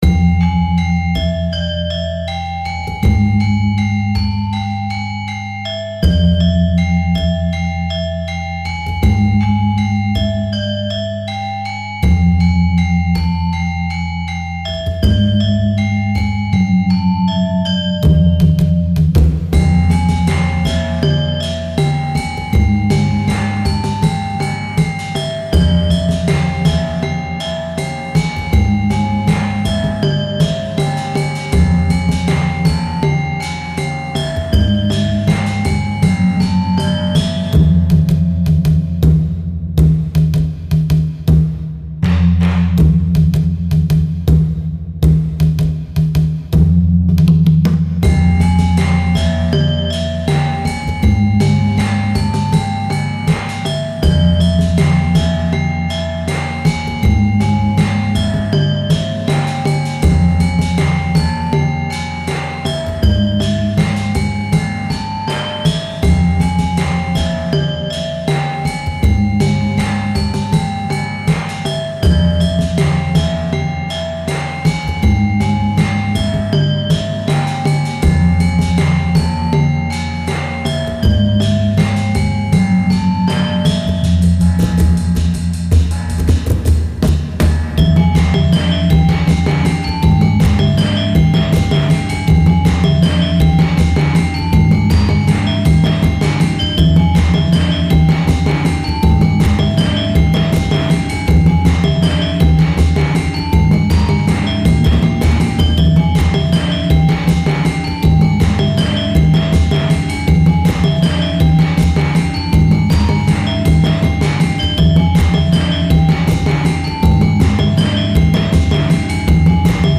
主な使用楽器は、ガムラン、金属打楽器、低音パーカッションなど。
• ジャンル：ワールドミュージック／民族系BGM／ファンタジー／アンビエント
• 雰囲気：神秘的 / 不穏 / 儀式的 / 幻想的